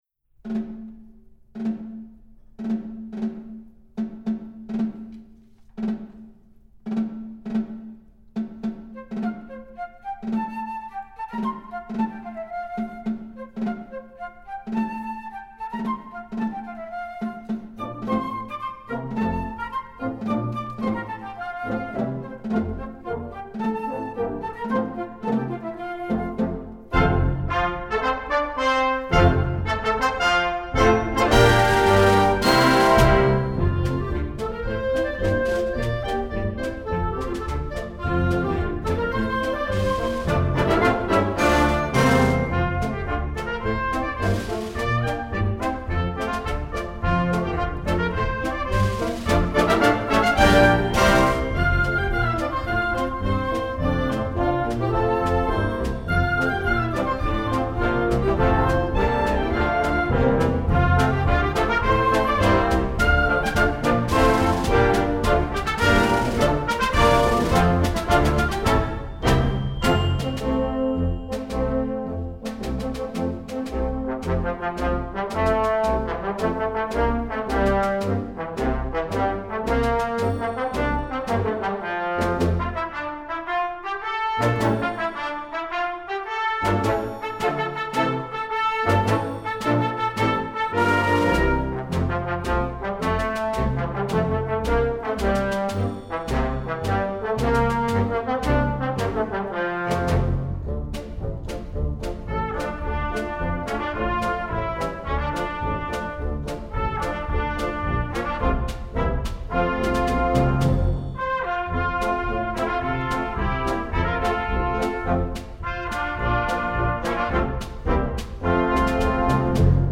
Voicing: Concert March